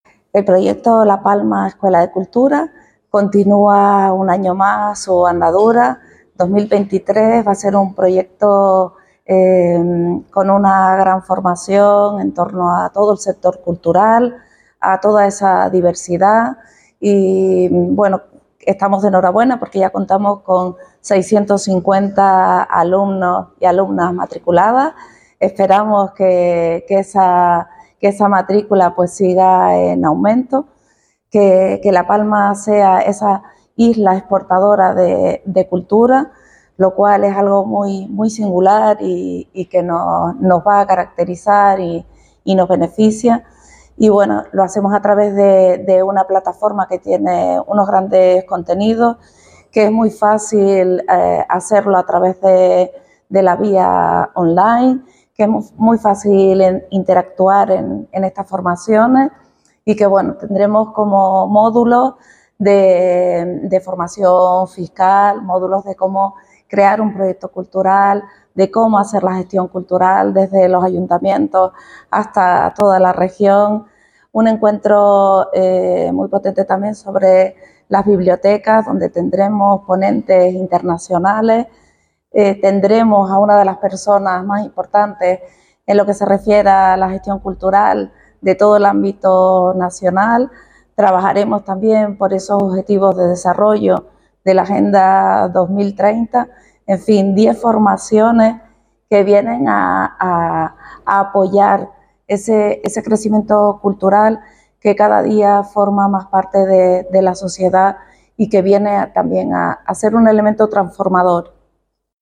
Declaraciones_audio_Jovita_Monterrey_La_Palma_Escuela_de_Cultura.mp3